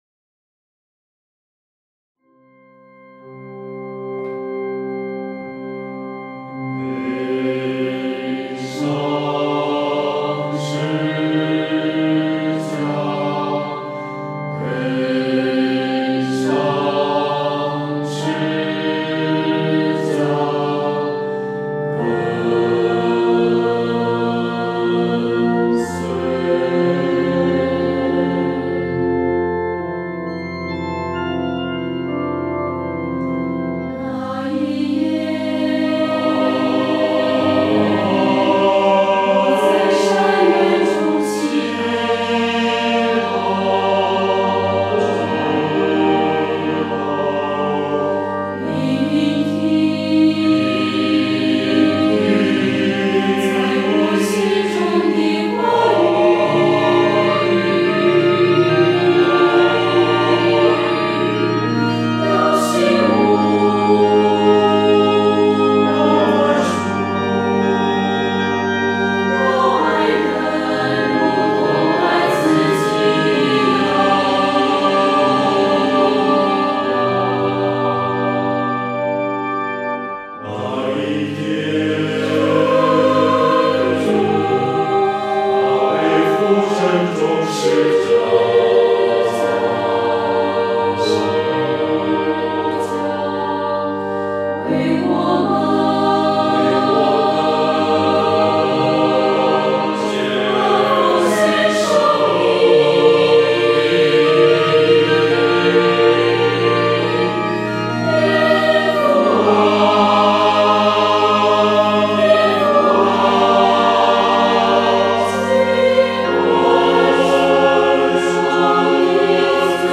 这是一首管弦乐四部合唱作品，作曲家希望透过这样的和声张力，能在短短几分钟内，把歌词的意境表达出来，打进聆听者的心坎里。